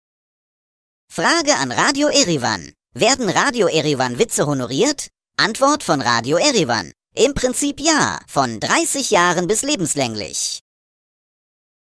Vorgetragen von unseren attraktiven SchauspielerInnen.